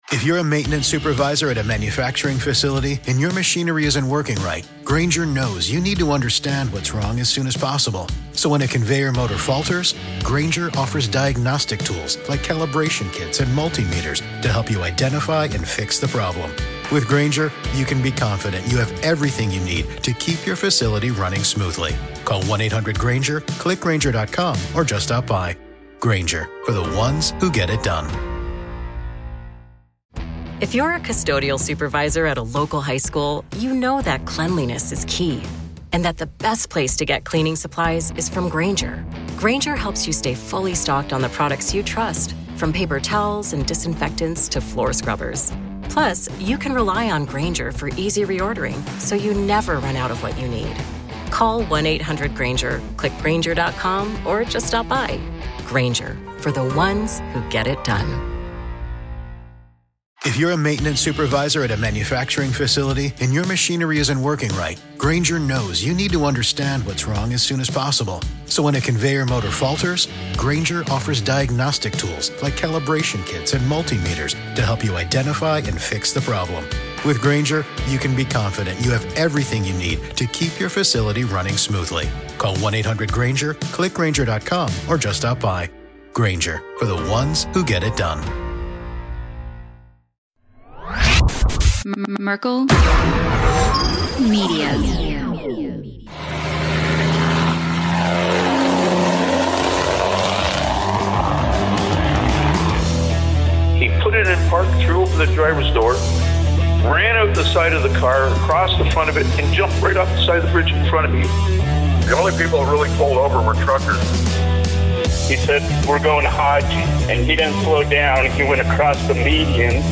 Buckle up for a lively ride as the hosts of Hammer Lane Legends tackle wild road stories to the ever-evolving world of trucking tech. With humor and candid confessions, they dive into AI-driven rigs, the struggles of ELDs, and the chaos of modern media. From dog-walking mishaps to political hot takes, this episode is packed with laughs, insights, and unfiltered reflections on life behind the wheel.